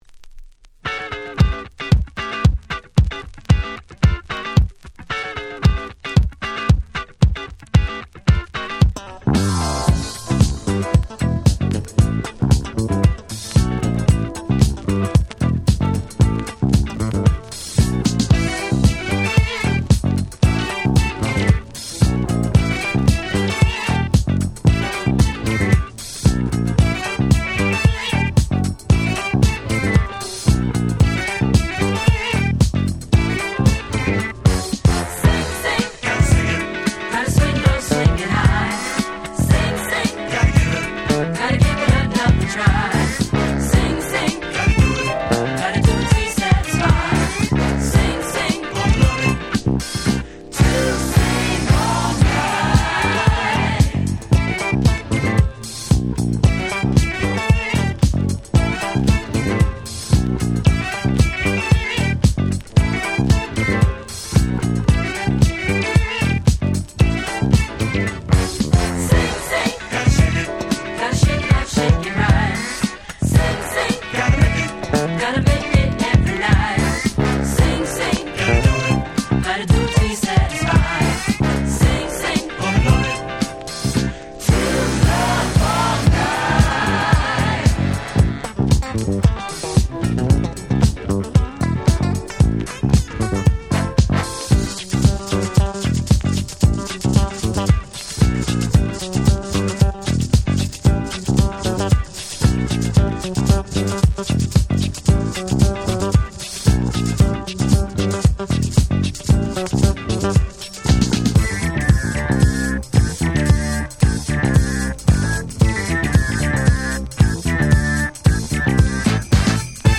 レーベルからの正規再発盤で音質もバッチリ！！
サルソウル ダンクラ ディスコ フリーソウル ダンスクラシックス Dance Classics